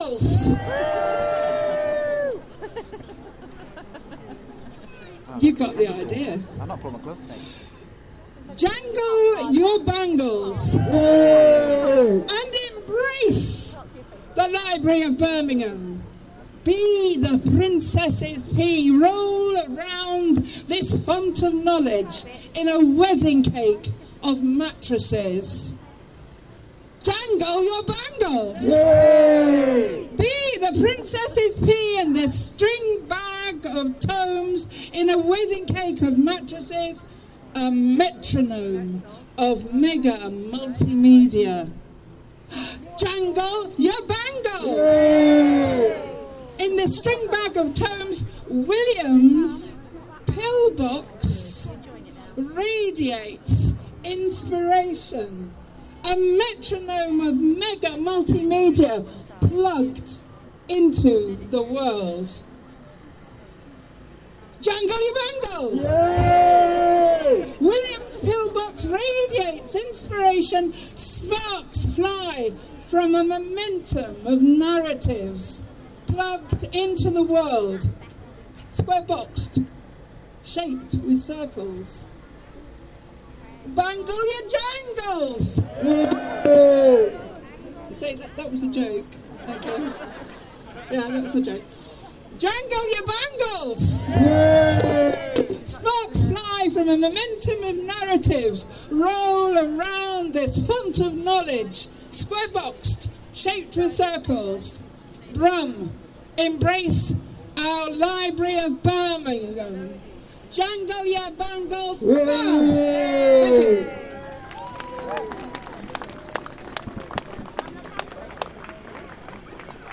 poem
at Rally to protest against cuts at the Library of Birmingham.